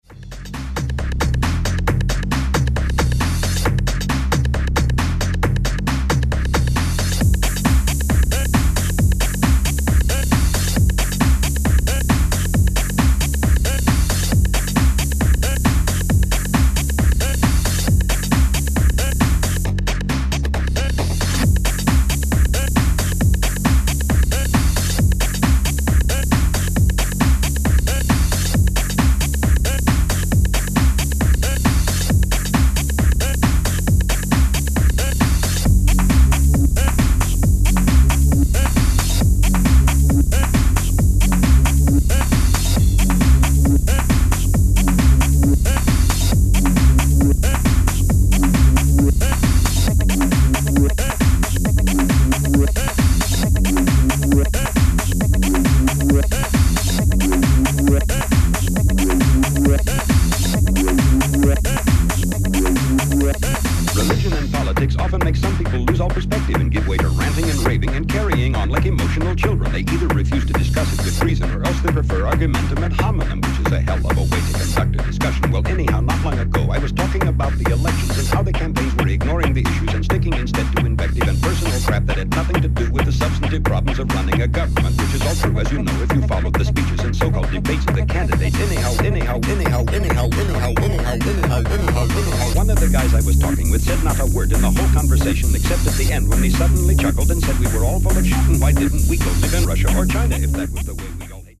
That vocal sample is ace.